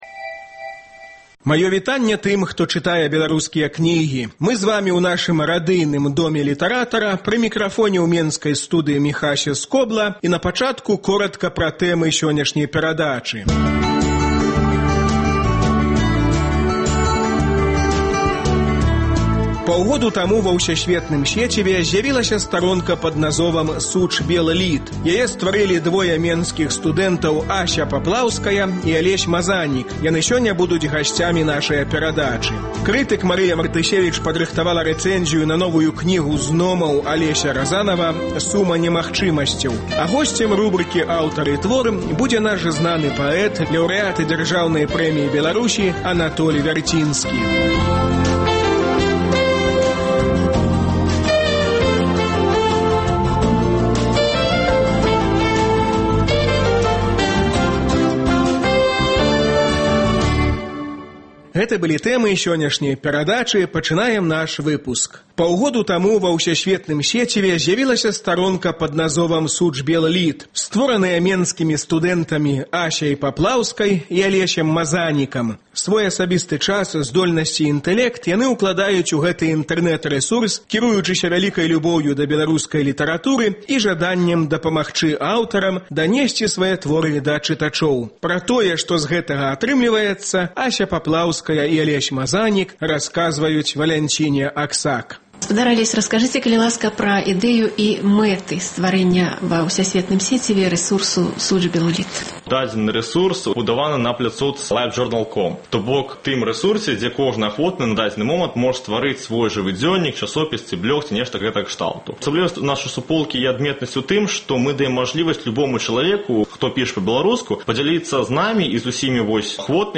У рубрыцы “Аўтар і твор” свае новыя вершы чытае Анатоль Вярцінскі.